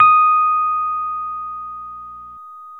RHODES CL0JR.wav